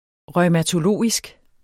Udtale [ ʁʌjmatoˈloˀisg ]